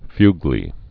(fŭglē)